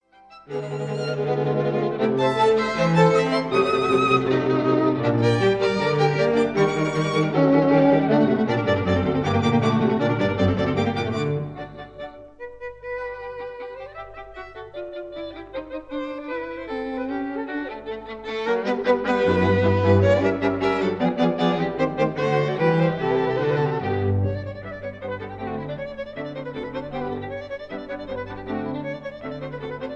Allegro molto